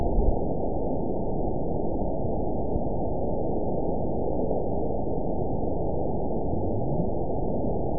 event 920440 date 03/25/24 time 07:08:09 GMT (1 year, 1 month ago) score 9.36 location TSS-AB01 detected by nrw target species NRW annotations +NRW Spectrogram: Frequency (kHz) vs. Time (s) audio not available .wav